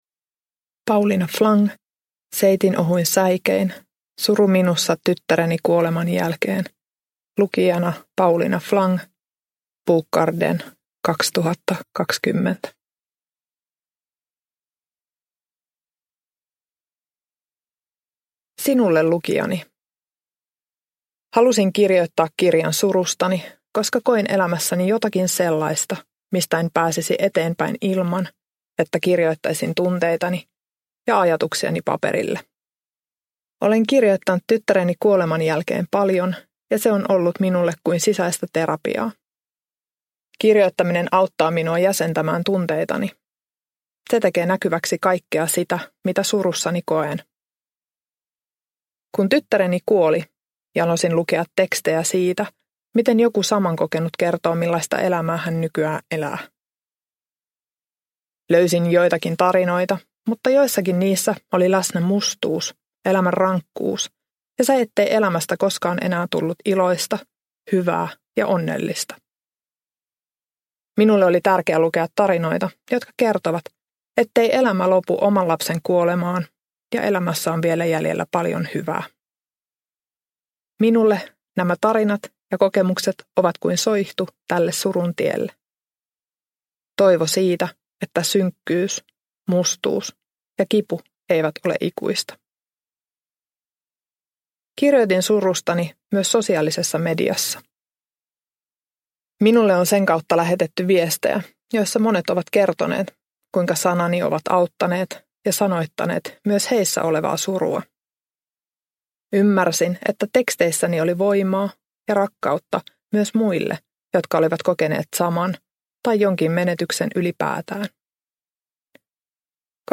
Seitinohuin säikein – Ljudbok – Laddas ner